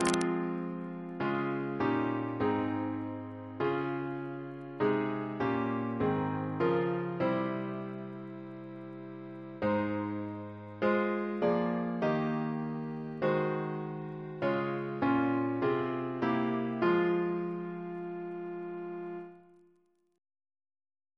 Double chant in F minor Composer: Henry Stonex (1823-1897) Reference psalters: ACB: 252; ACP: 54; CWP: 57; RSCM: 131